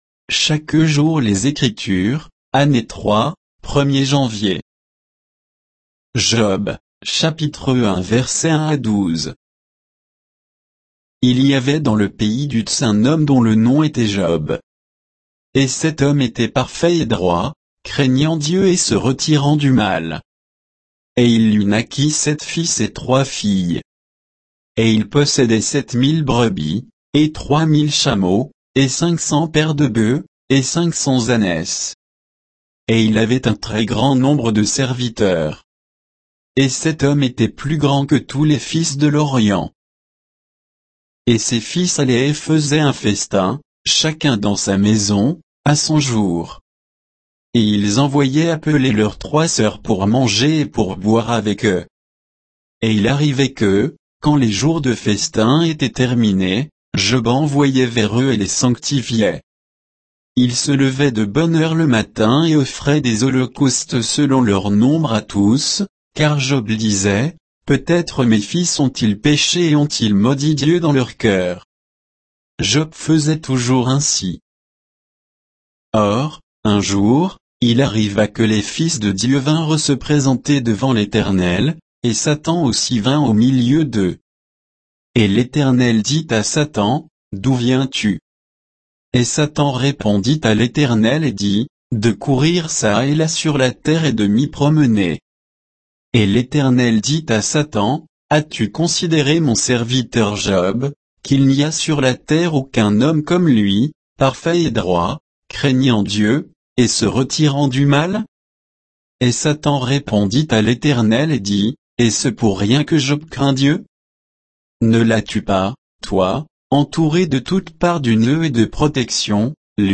Méditation quoditienne de Chaque jour les Écritures sur Job 1